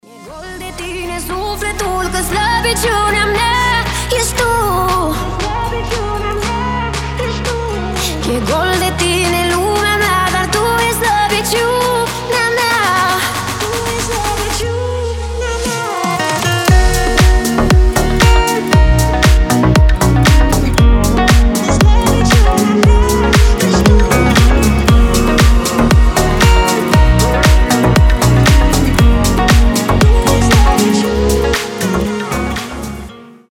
• Качество: 320, Stereo
гитара
deep house
мелодичные
красивая мелодия
нарастающие
красивый женский голос
Восхитительный румынский deep house